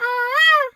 bird_peacock_squawk_14.wav